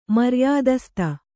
♪ maryādasta